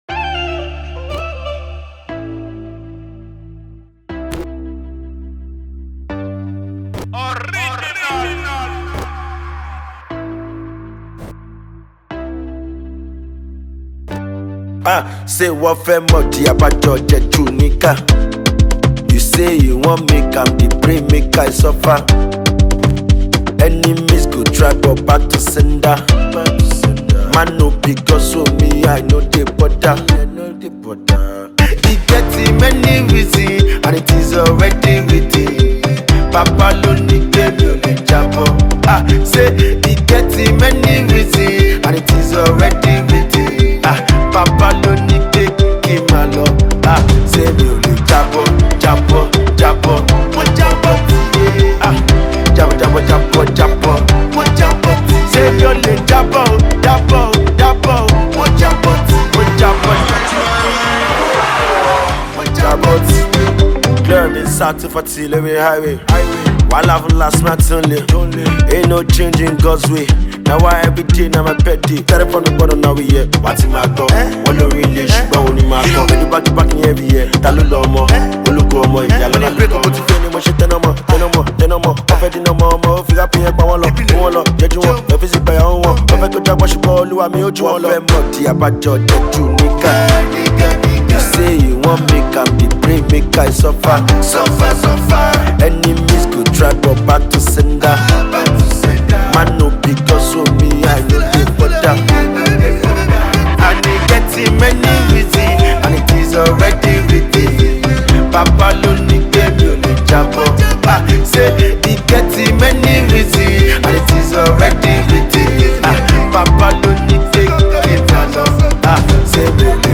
dance-tempo record
feel good song